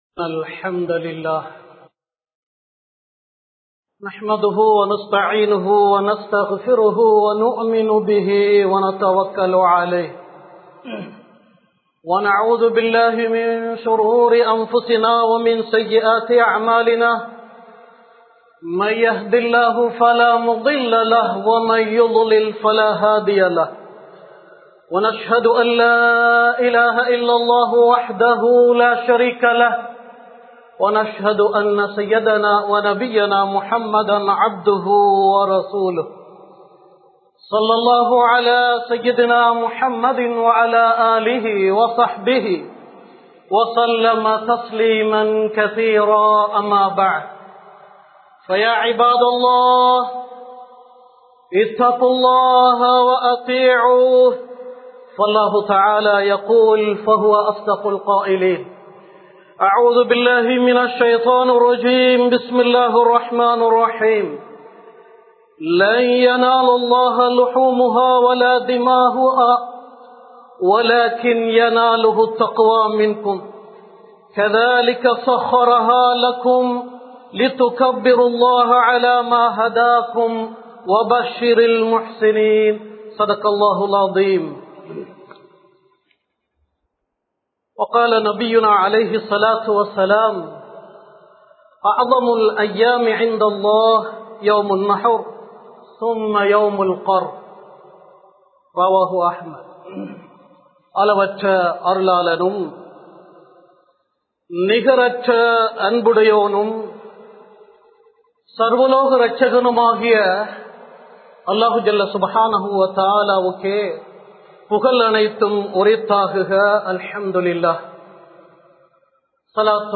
Allah Iyattriya Sattaththil Kurai Theadufavarhale (அல்லாஹ் இயற்றிய சட்டத்தில் குறை தேடுபவர்களே!) | Audio Bayans | All Ceylon Muslim Youth Community | Addalaichenai
Colombo 03, Kollupitty Jumua Masjith